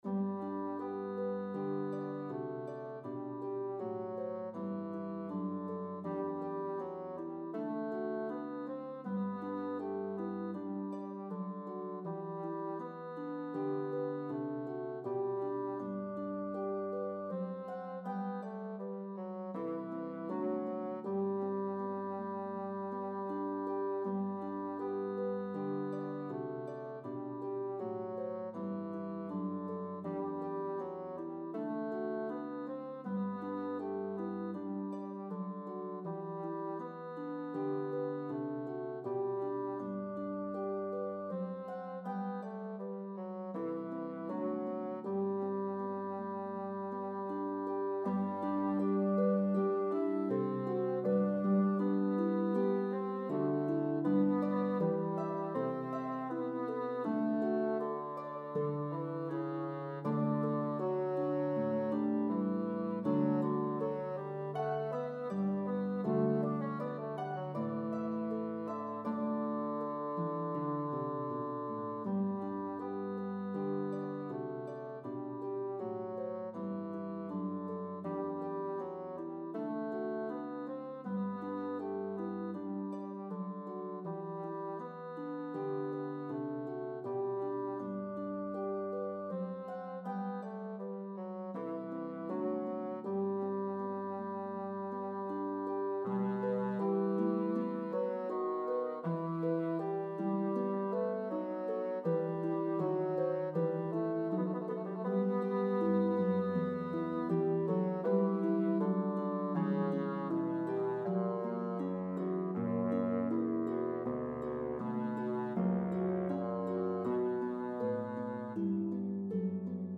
This well known Baroque piece